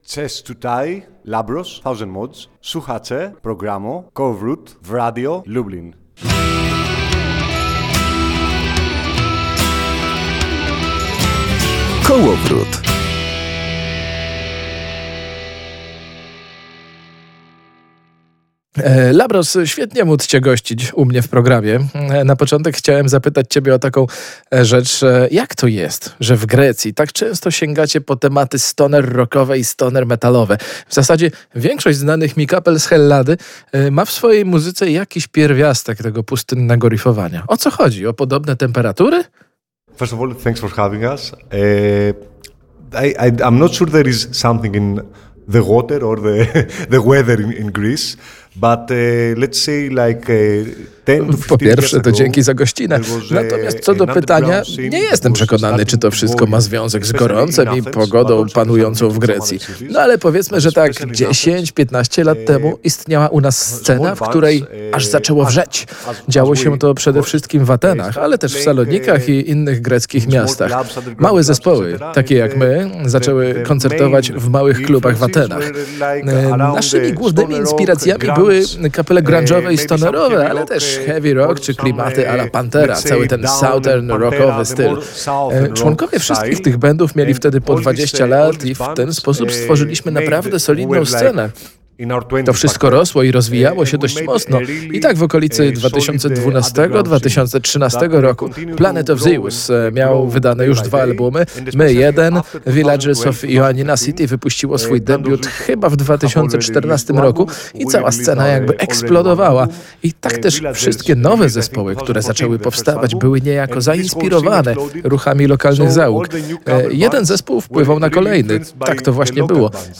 Materiał audio pochodzi z audycji „Kołowrót” i oryginalnie został wyemitowany 26.11.2024.